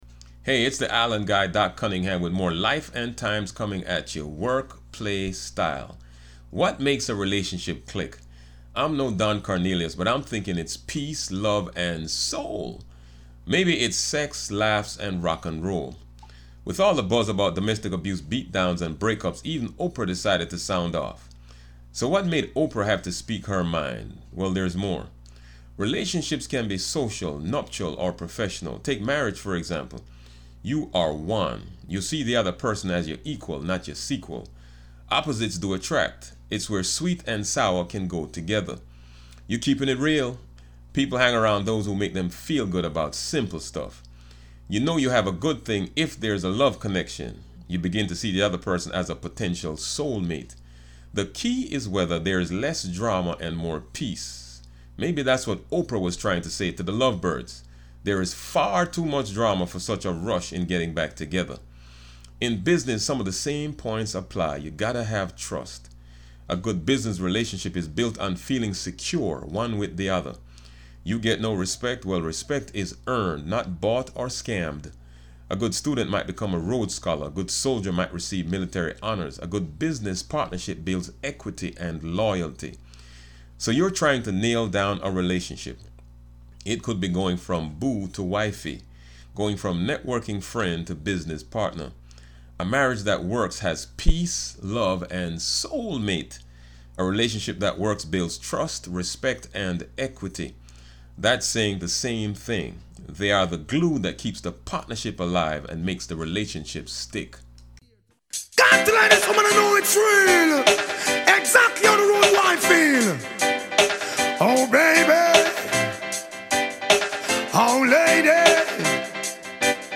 Play ‘R & B Flava’ Podcast track here